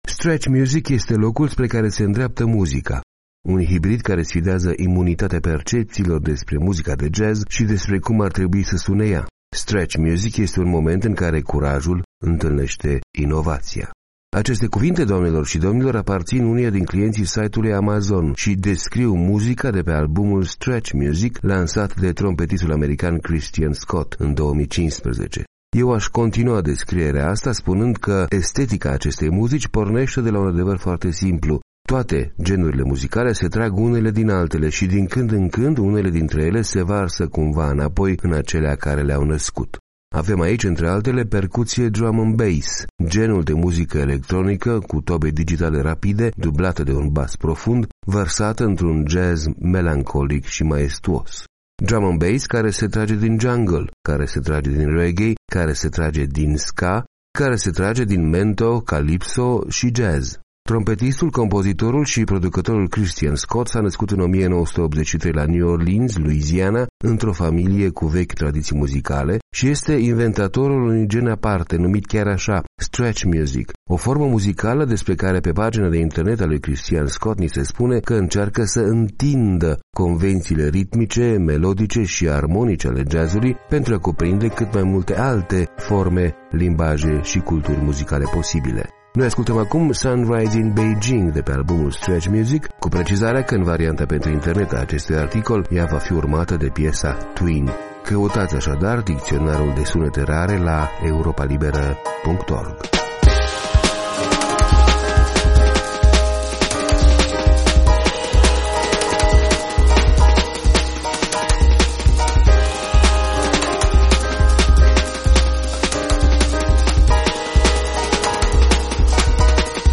O formă muzicală care încearcă să „întindă” convențiile ritmice, melodice și armonice ale jazz-ului.